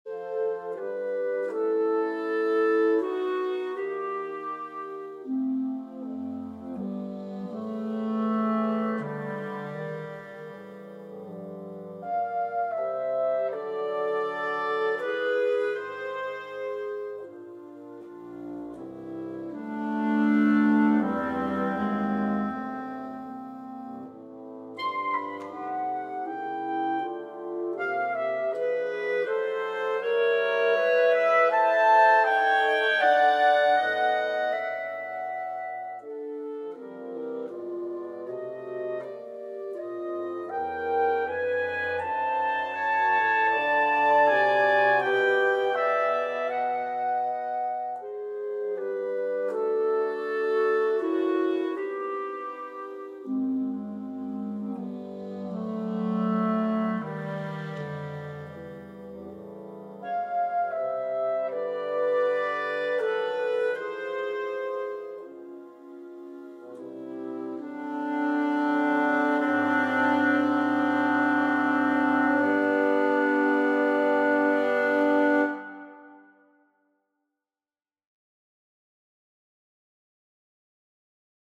Persichetti Exercise 3 - 50 for Woodwind Quartet